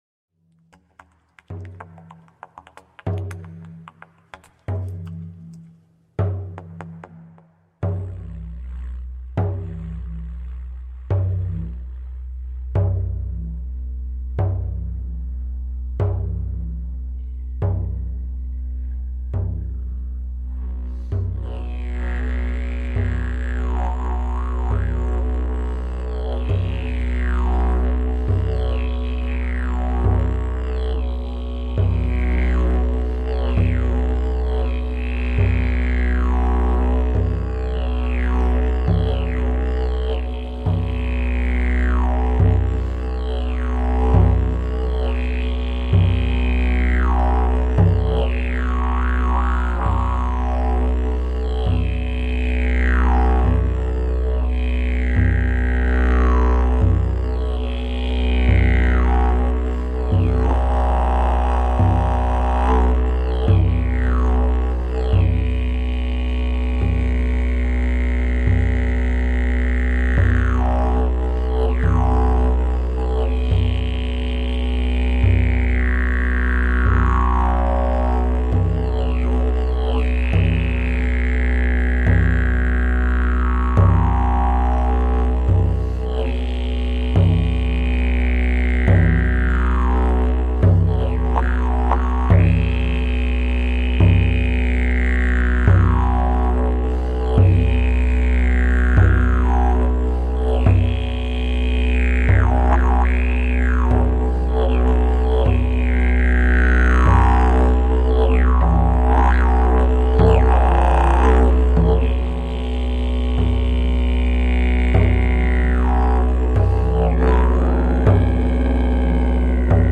Crystal Singing Bowls, Didgeridoo and Drum Meditation.
30 minutes, Didgeridoo and Drum Meditation